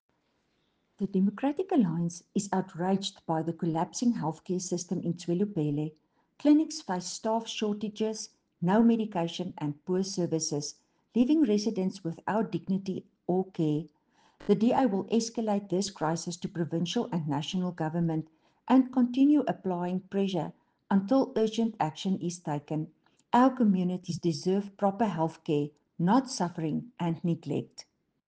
Afrikaans soundbites by Cllr Estelle Pretorius and